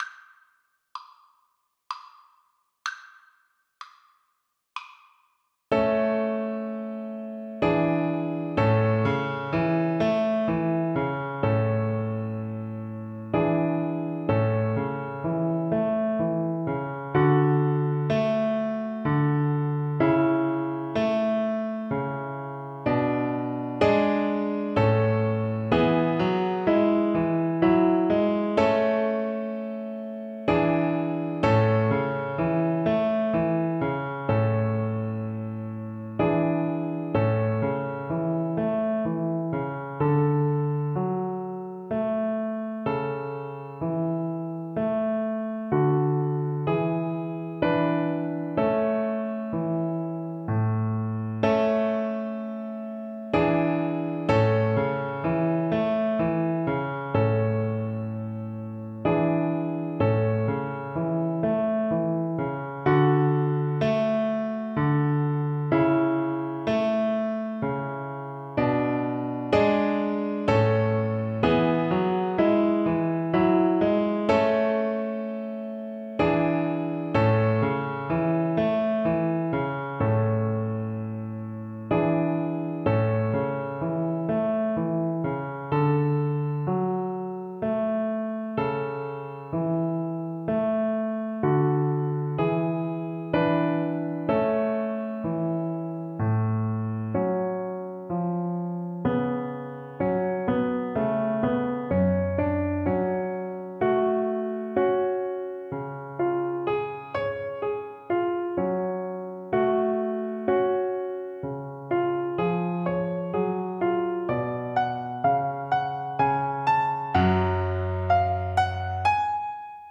Cello version
Moderato
3/4 (View more 3/4 Music)
Classical (View more Classical Cello Music)